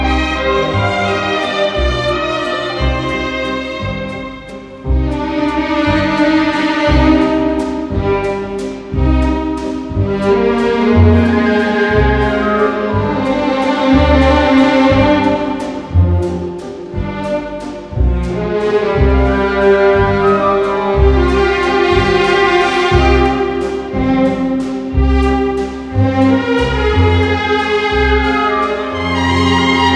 Classical Crossover